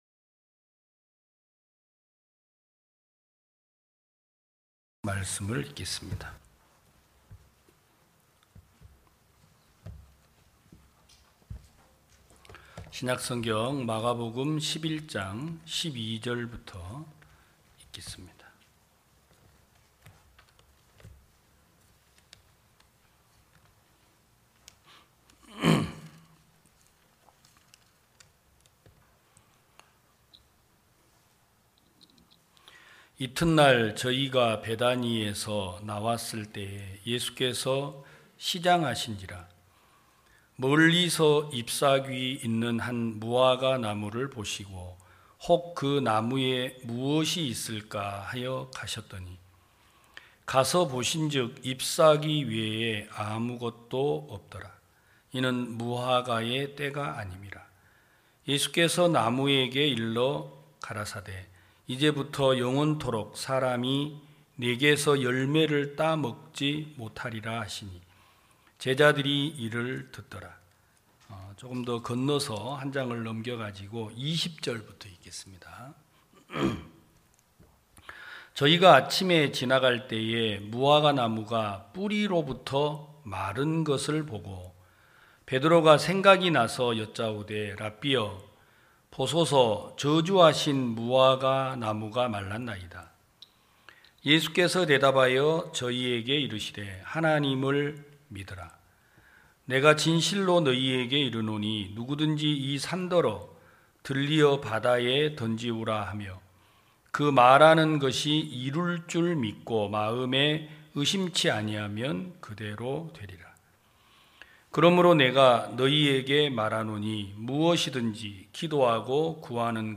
2021년 08월 08일 기쁜소식부산대연교회 주일오전예배
성도들이 모두 교회에 모여 말씀을 듣는 주일 예배의 설교는, 한 주간 우리 마음을 채웠던 생각을 내려두고 하나님의 말씀으로 가득 채우는 시간입니다.